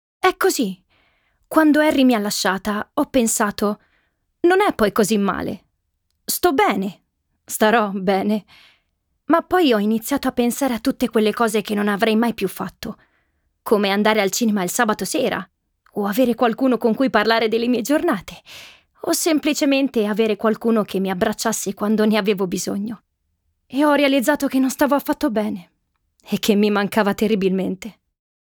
demo audiolibro
caratterizzazioni varie da bambini, ragazzini a donne